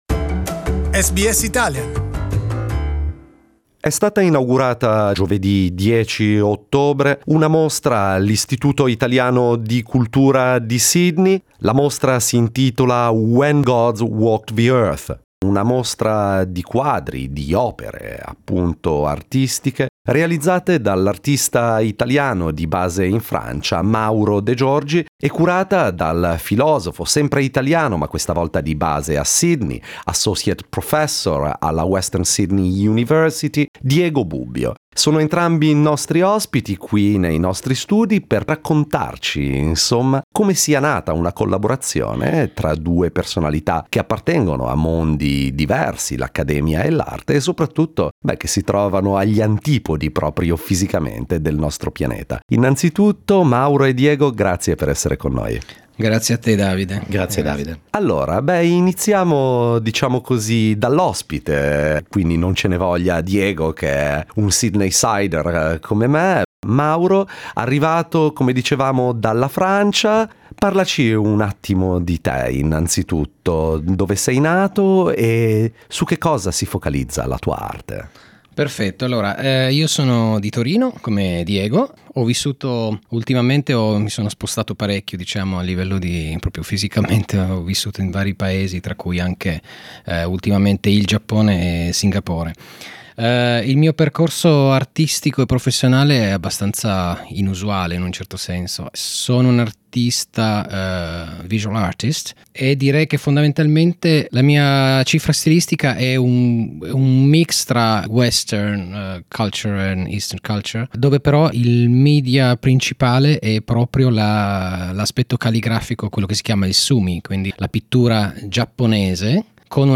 Listen to our interview (in Italian)